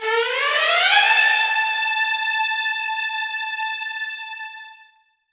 strTTE65023string-A.wav